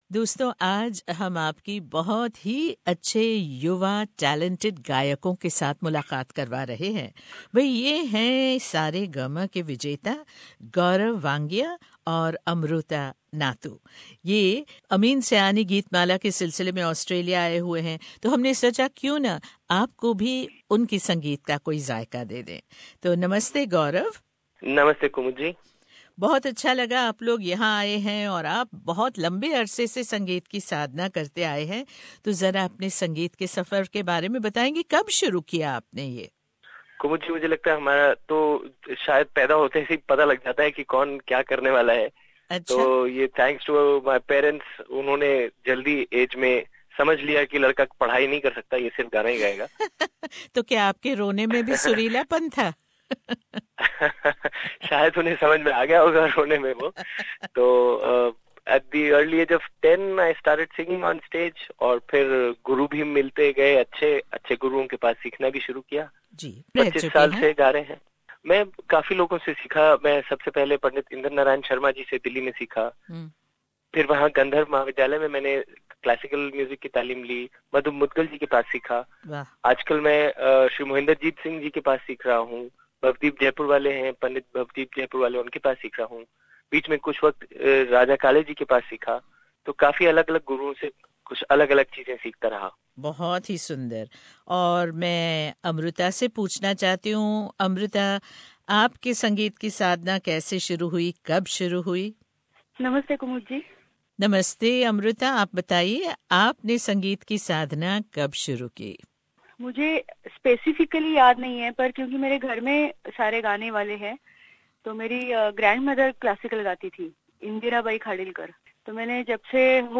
हमने बातचीत की है दो बहुत ही होनहार गायकों,
सुनिये उनका मधुर गायन और मीठी बातें